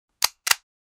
pistol-equipped-45umtdoi.wav